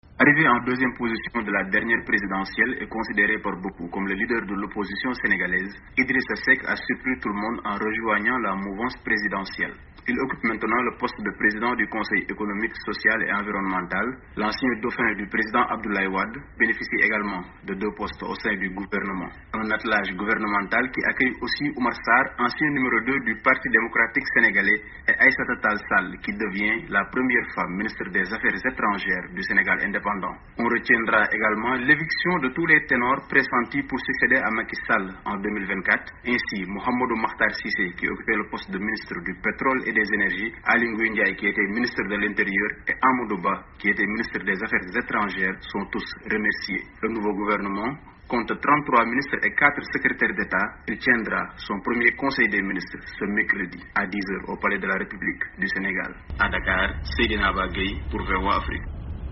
Le Président Macky Sall a procédé ce dimanche 1er novembre à la nomination d'un nouveau gouvernement. Le poste de 1er Ministre tant attendu n'est toujours pas de retour, la surprise plutôt est l'arrivée de l'opposant Idrissa Seck à la tete du Conseil Économique Social et Environnemental. de notre correspondant à Dakar